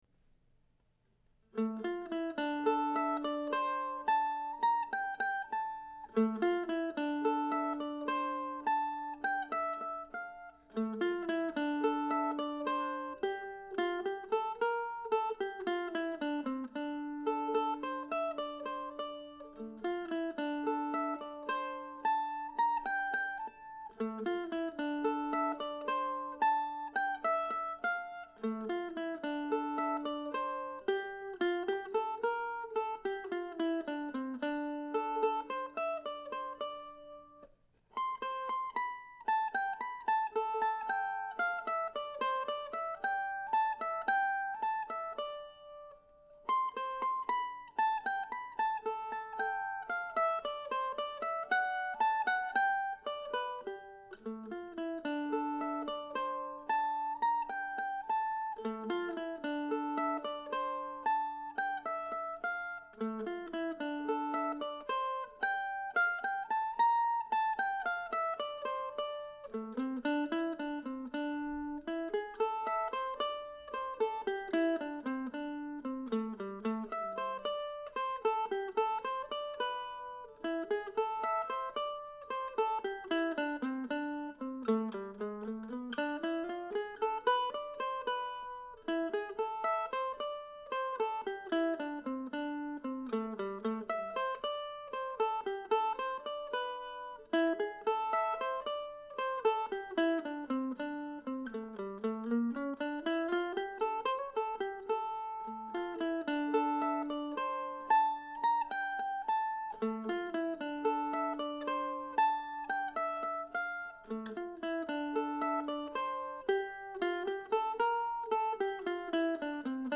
This week's new tune is a nice quiet number for solo mandolin.